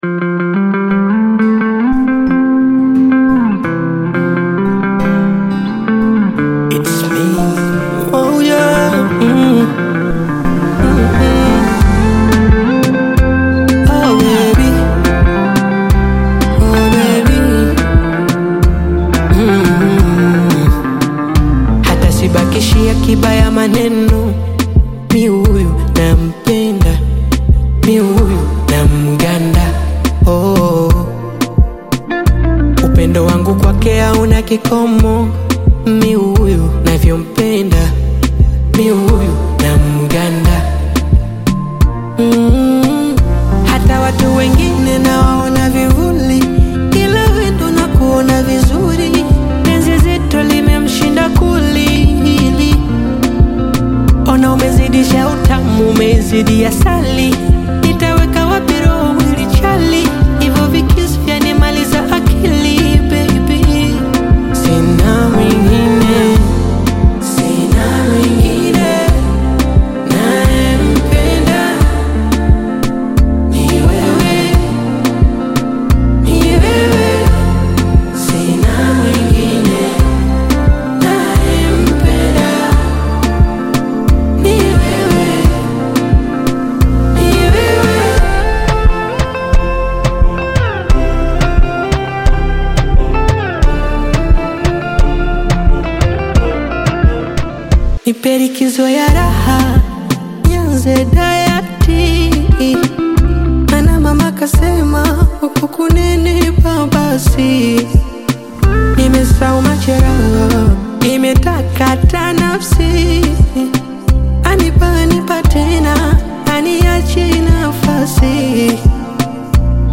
smooth Afro-Pop single
With expressive vocal delivery and polished production